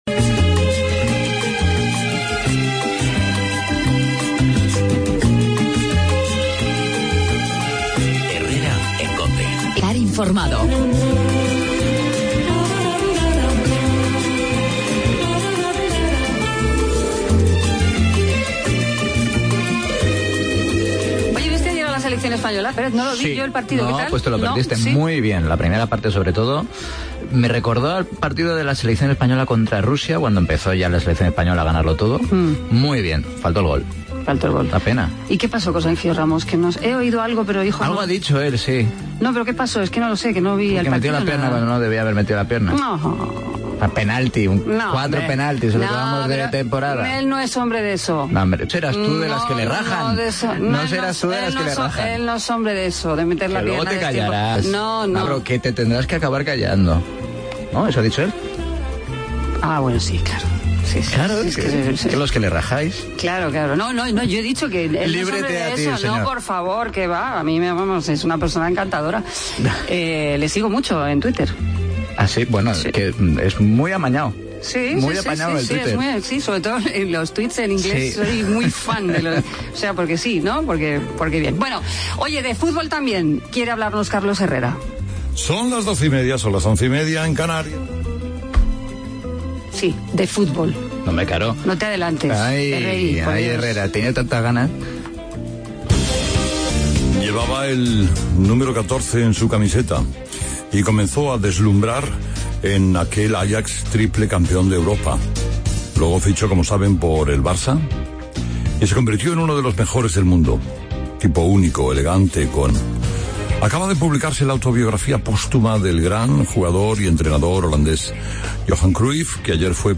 Actualidad y entrevista con la consejera Patricia Franco por la inauguración de FARCAMA.